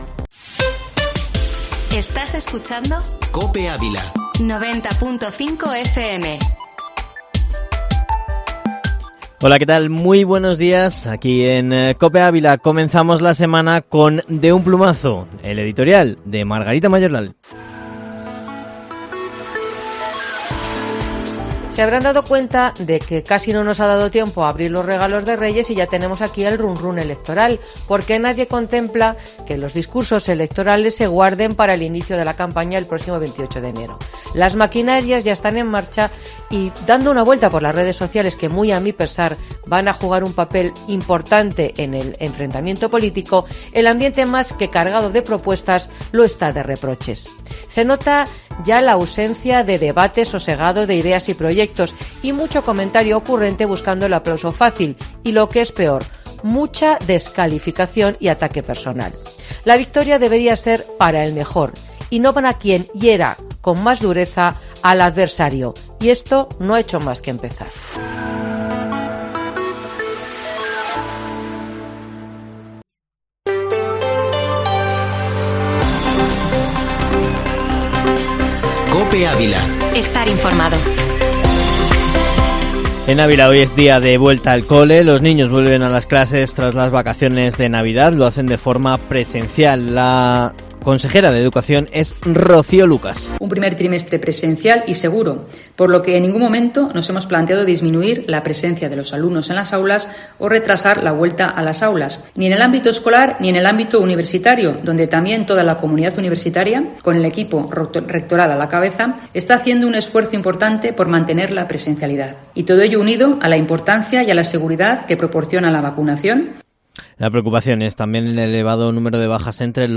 Informativo Matinal Herrera en COPE Ávila -10-enero